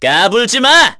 Ezekiel-Vox_Skill1_kr.wav